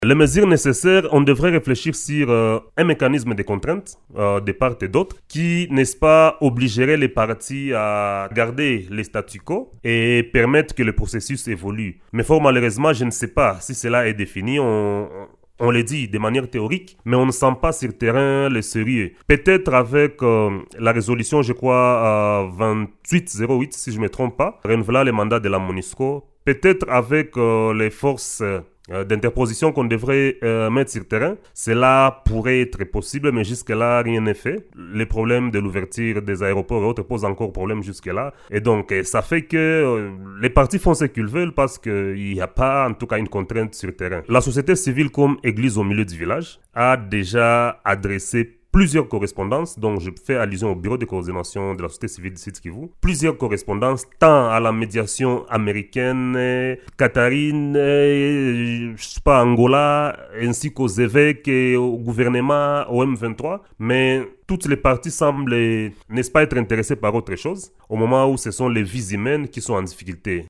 au cours d’une interview accordée à Radio Maendeleo